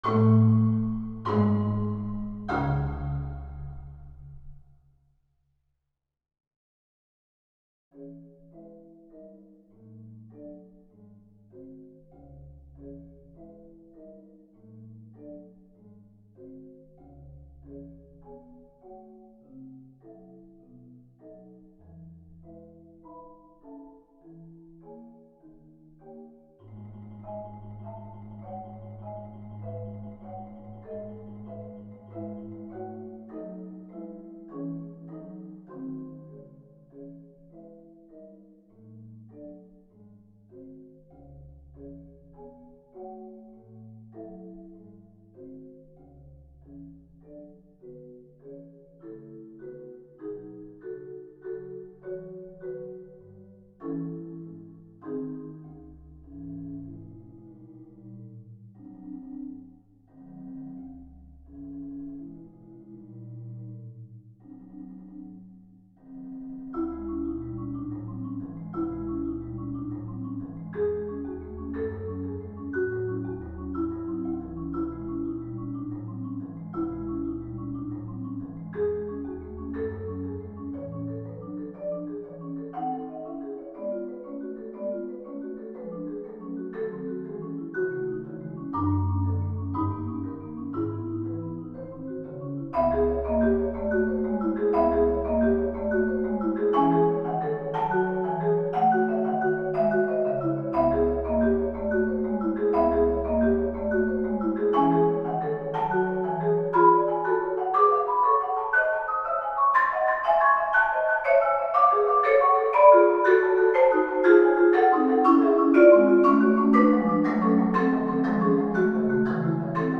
Genre: Marimba (4-mallet)